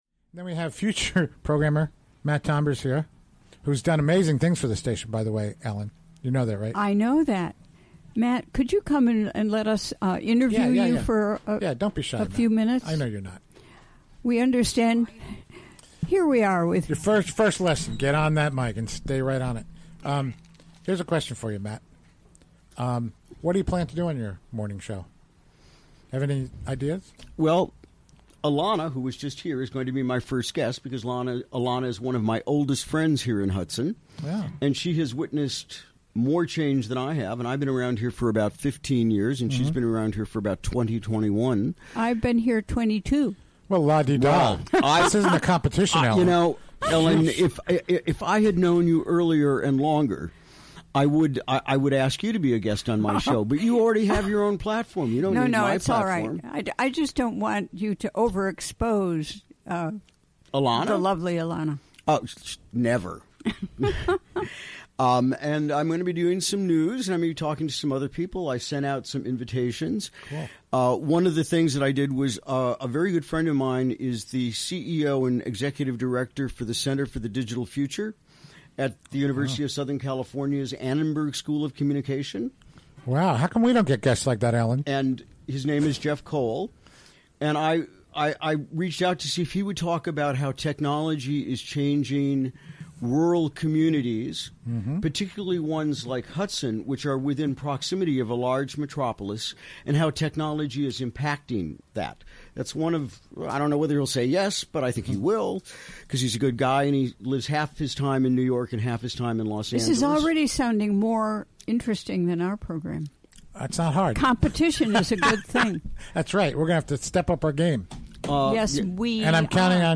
Recorded during the WGXC Afternoon Show Thursday, March 30, 2017.